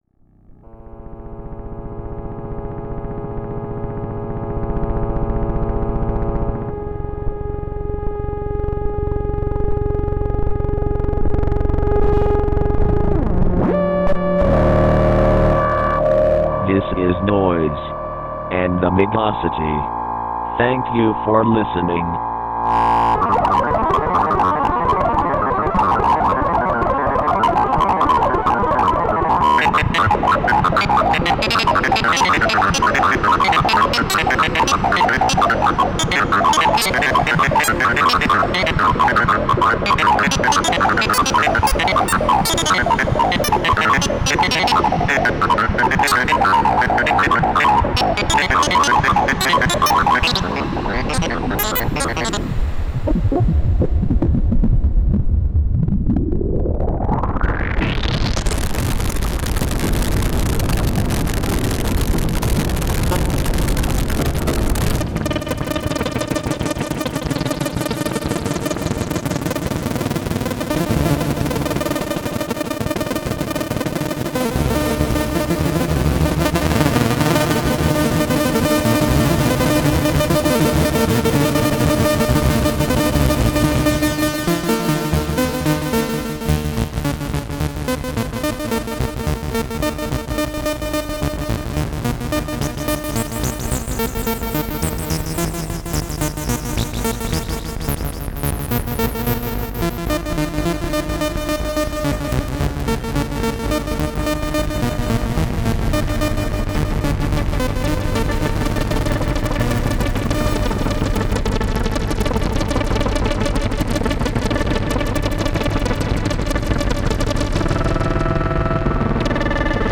This month on the program, a mixture of synthesizers and strange vinyl records.